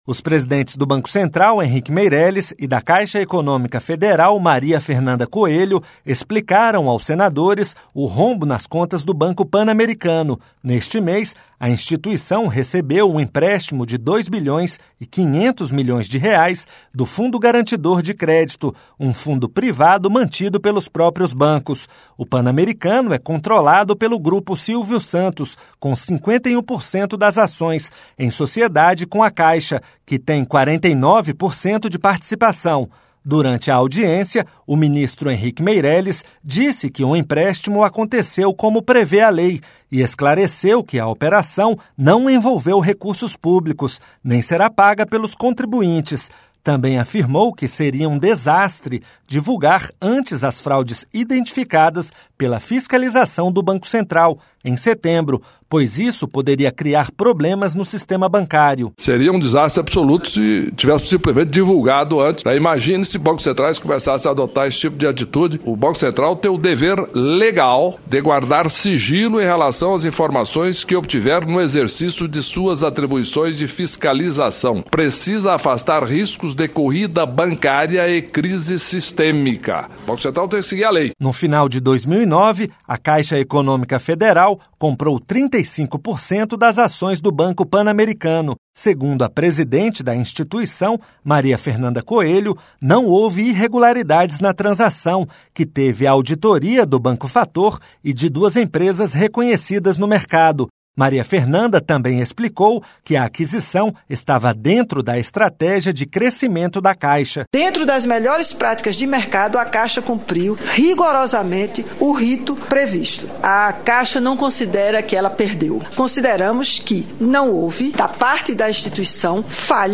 LOC: AS DUAS AUTORIDADES PARTICIPARAM DE UMA AUDIÊNCIA PÚBLICA SOBRE O ASSUNTO NESTA QUARTA-FEIRA NAS COMISSÕES DE ASSUNTOS ECONÔMICOS E DE CONSTITUIÇÃO E JUSTIÇA. Os presidentes do Banco Central, Henrique Meirelles, e da Caixa Econômica Federal, Maria Fernanda Coelho, explicaram aos senadores o rombo nas contas do Banco Panamericano.